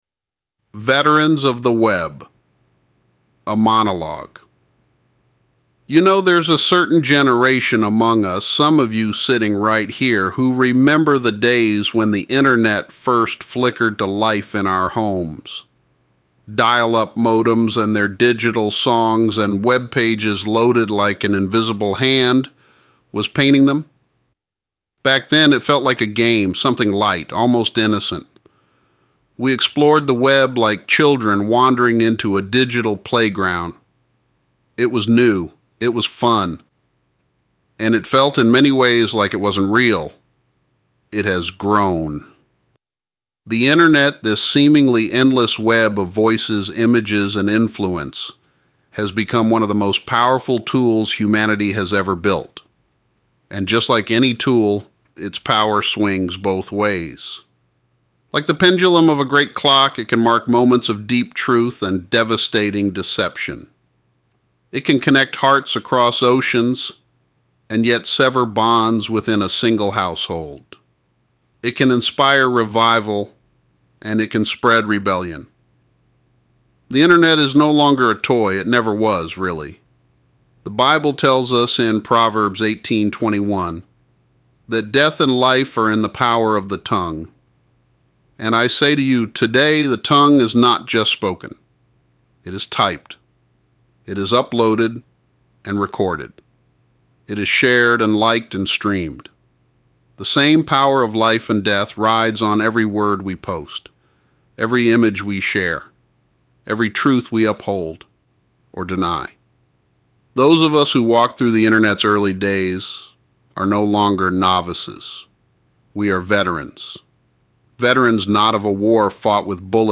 A Monologue: